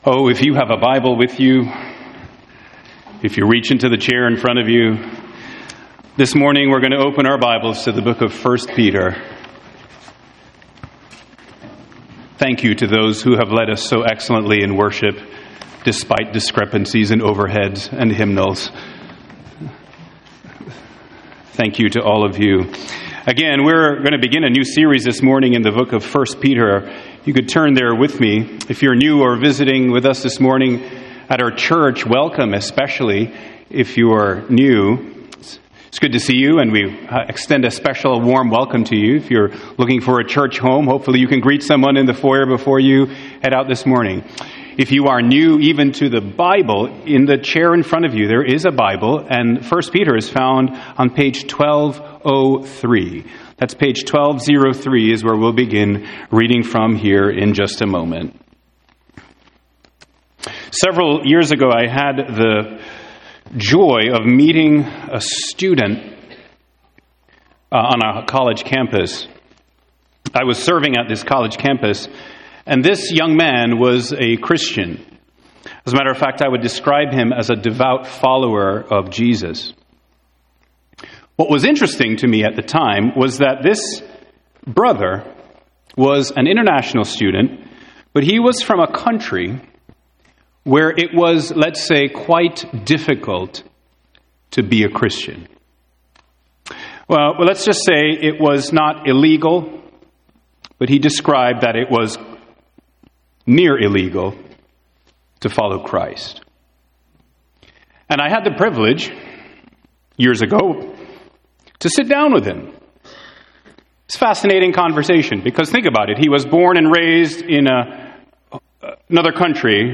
Sermon Outline Main point: When sorrow is added, God's grace multiplies. 1.